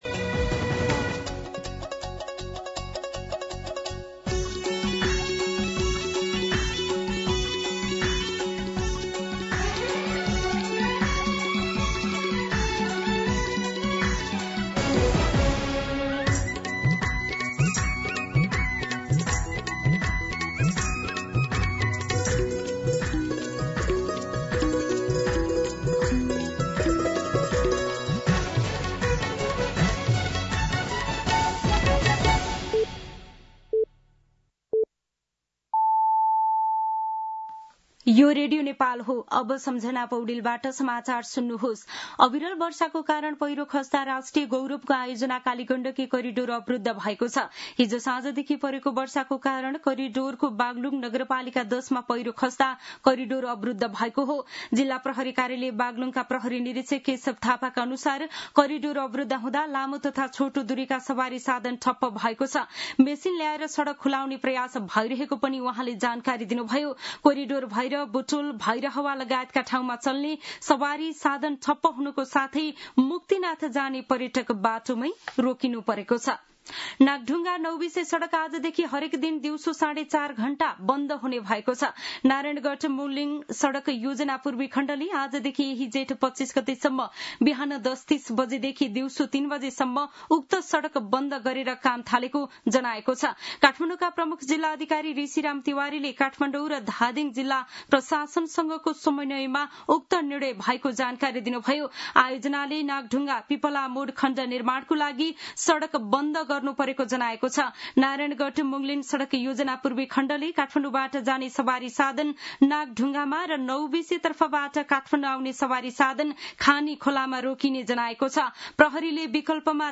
दिउँसो १ बजेको नेपाली समाचार : १० जेठ , २०८२
1-pm-Nepali-News-3.mp3